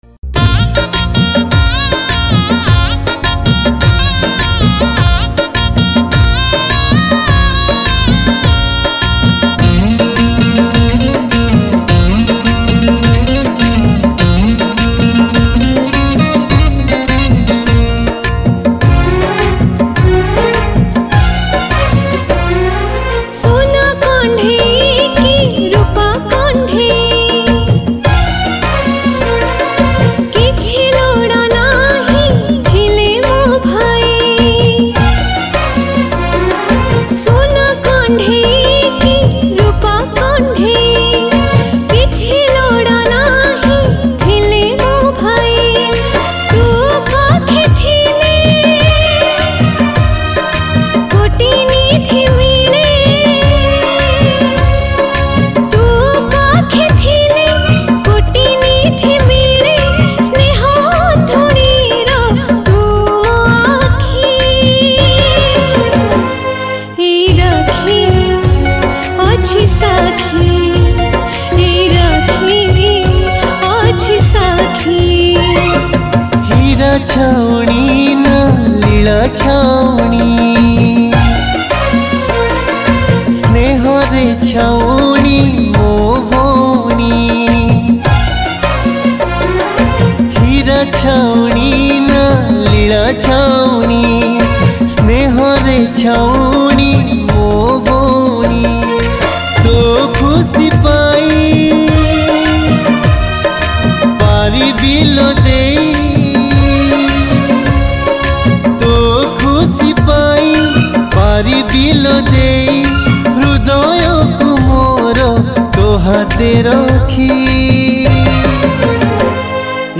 Rakhi Purnima Special Song